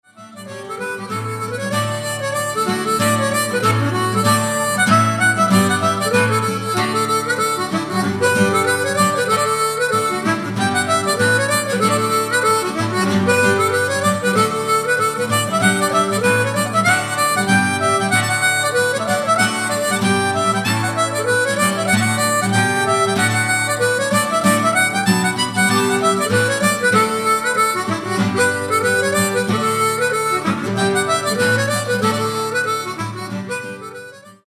reels
• Diatonic harmonicas
Acoustic guitar, bass guitar, fiddle, mandolin, vocals